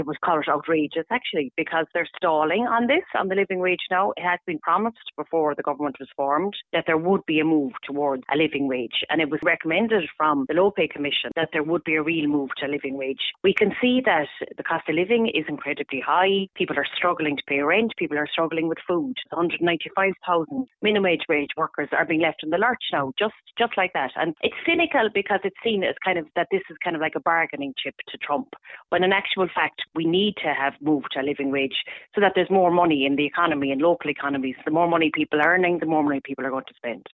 Senator Nessa Cosgrove, Labour’s Workers’ Rights Spokesperson, says it’s an unacceptable delay……………